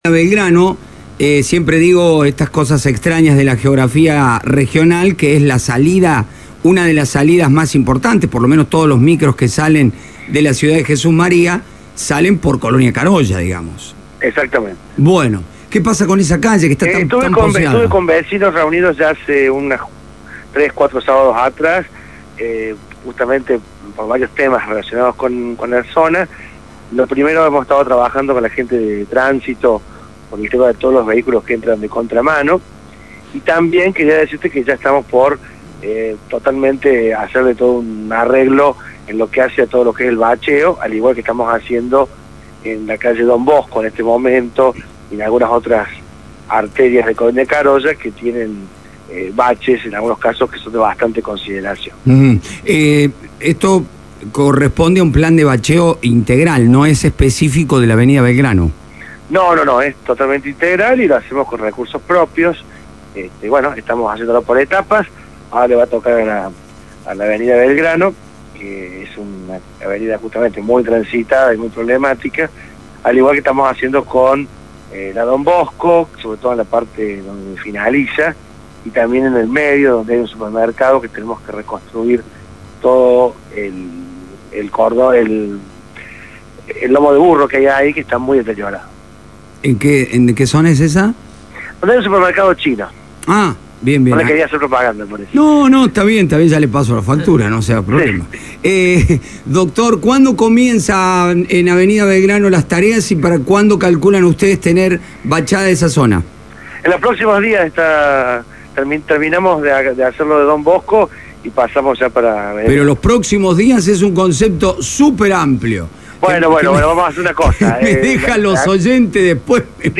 AUDIO: GUSTAVO BRANDÁN, INTENDENTE DE COLONIA CAROYA.